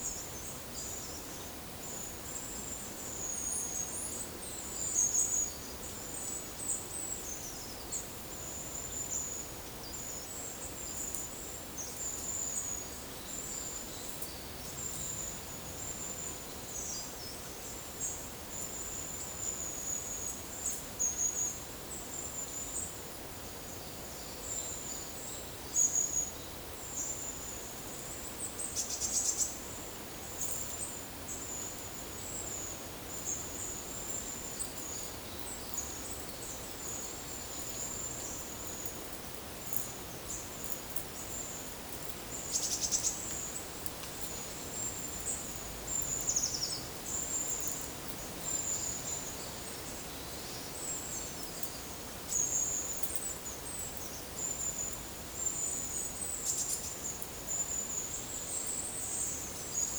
PEPR FORESTT - Monitor PAM - Renecofor
Lophophanes cristatus
Aegithalos caudatus
Certhia familiaris
Cyanistes caeruleus
Certhia brachydactyla